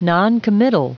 Prononciation du mot noncommittal en anglais (fichier audio)
Prononciation du mot : noncommittal